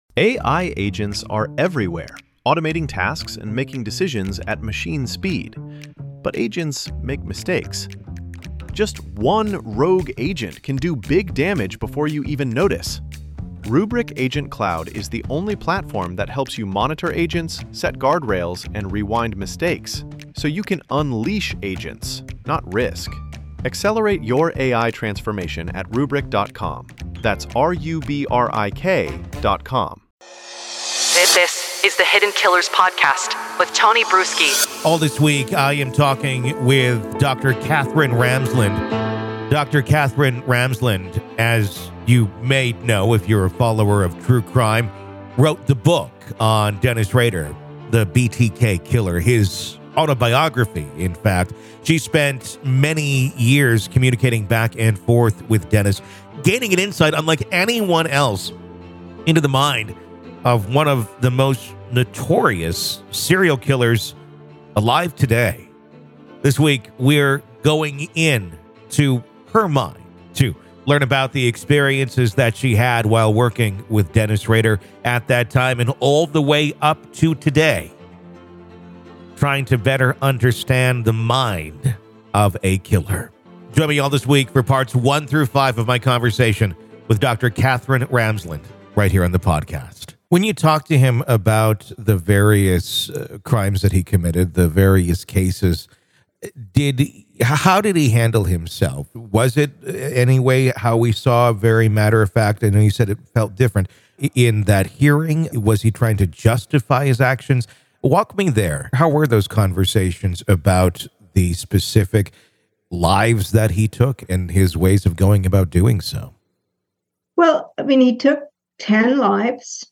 Dr. Katherine Ramsland Interview Behind The Mind Of BTK Part 3